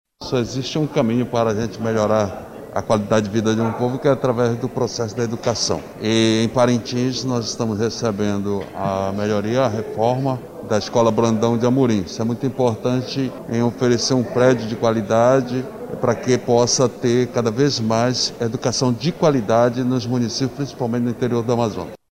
A Escola Estadual Brandão de Amorim será a unidade que passará pelo processo de revitalização. Como destaca o prefeito, Bi Garcia.
Sonora-Bi-Garcia-–-prefeito-de-Parintins.mp3